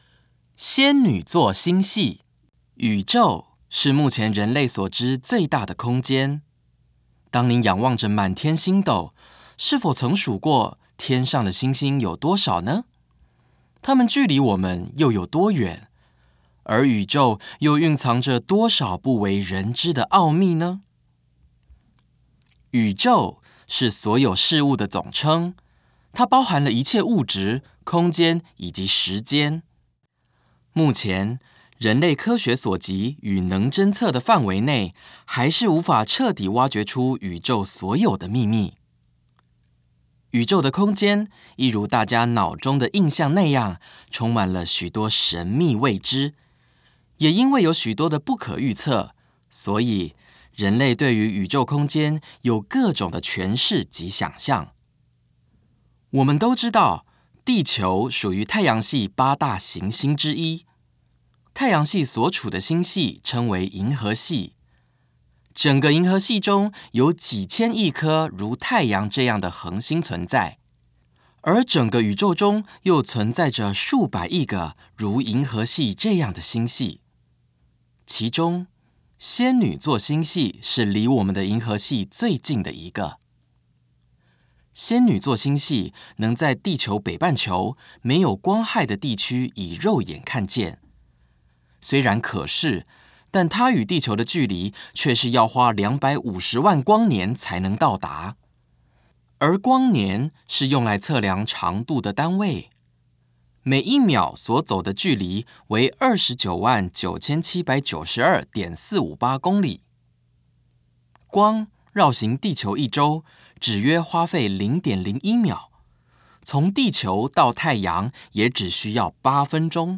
語音導覽